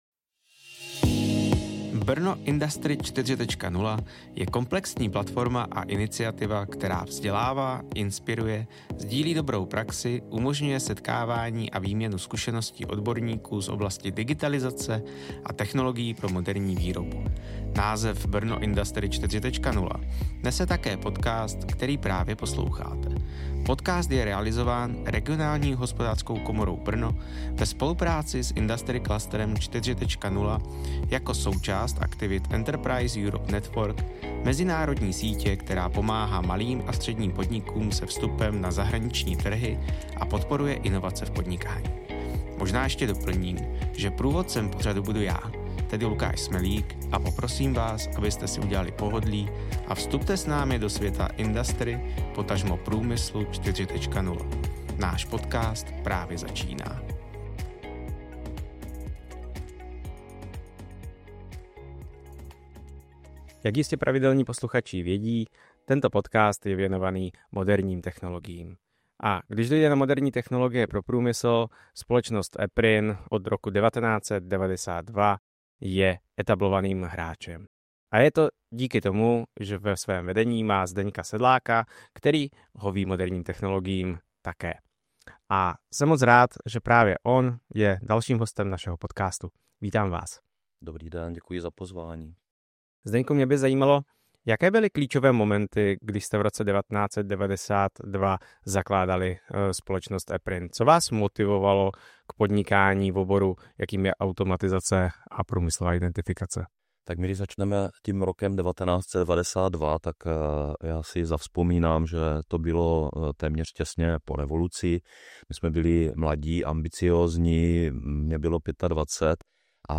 V rozhovoru rozkrýváme, co skutečně znamená průmyslová robotizace a digitalizace v české firmě, jaké jsou nejčastější překážky a proč bez jasné s...